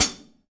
Index of /kb6/Akai_MPC500/1. Kits/Amb Rm Kit